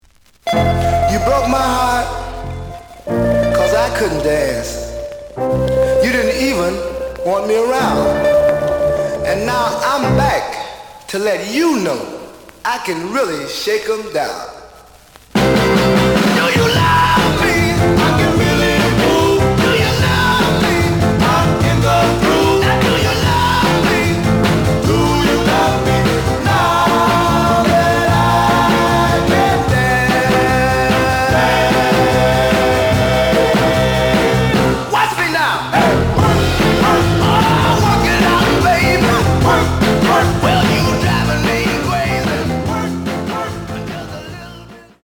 The audio sample is recorded from the actual item.
●Genre: Soul, 60's Soul
Some noise on parts of A side.